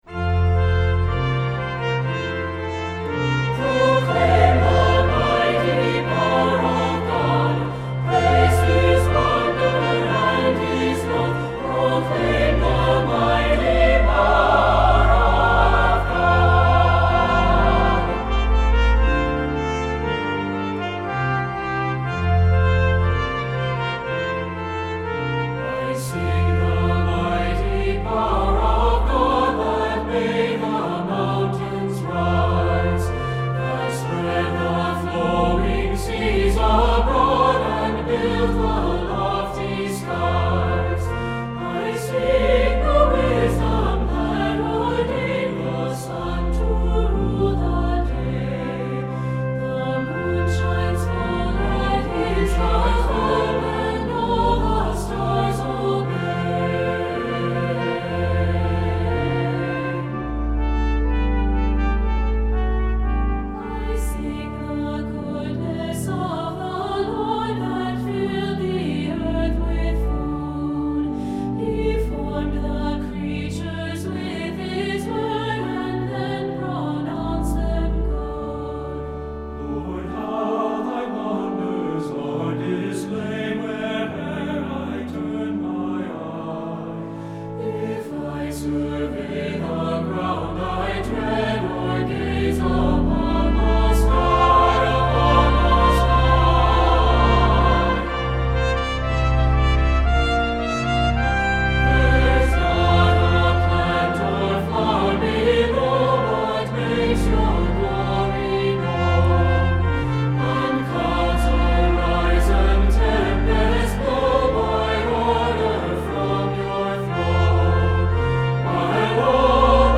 Voicing: SATB and Trumpet